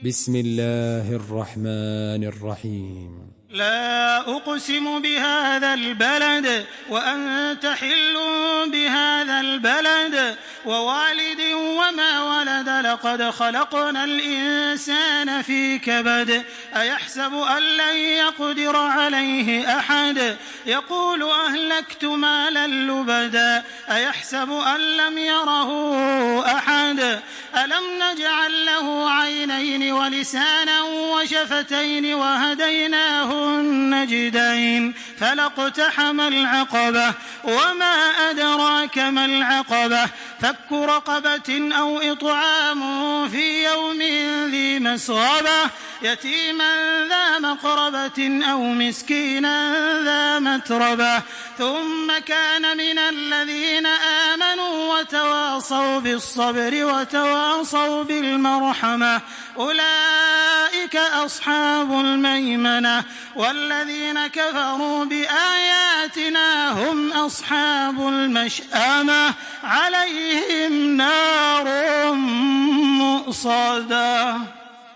سورة البلد MP3 بصوت تراويح الحرم المكي 1426 برواية حفص
مرتل